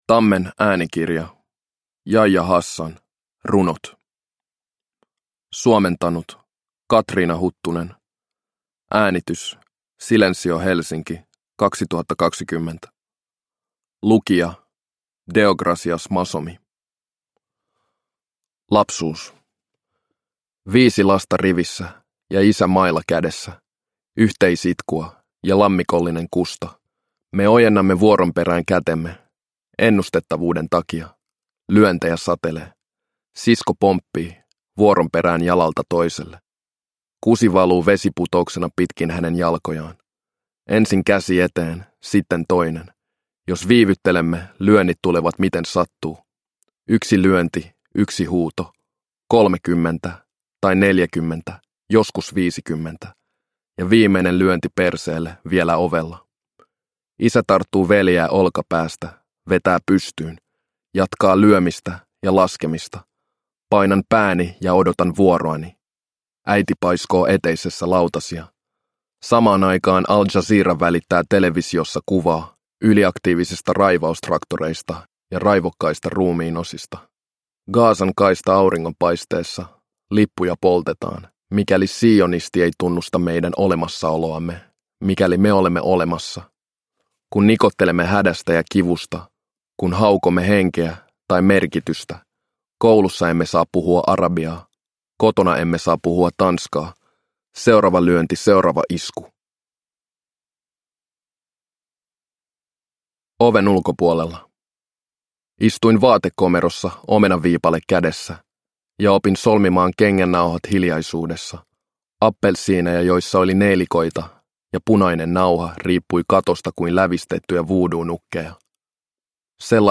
Yahya Hassan – Ljudbok